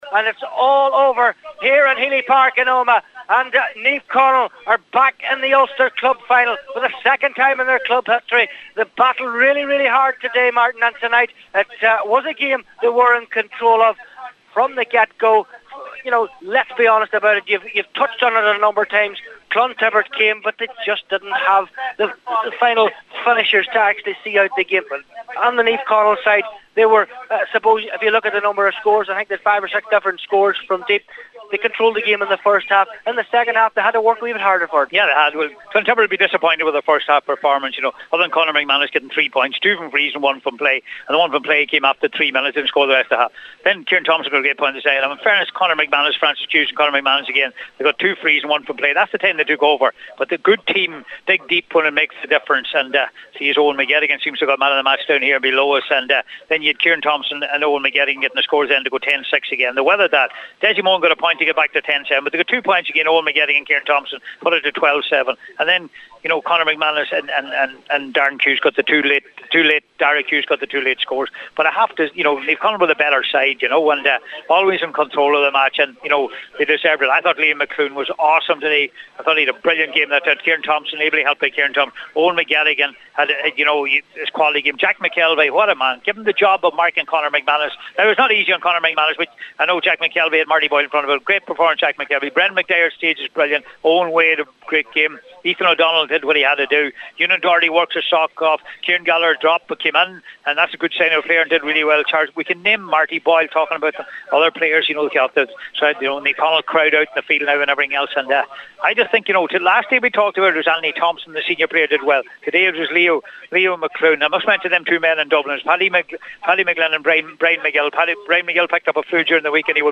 report for Highland Radio Sport…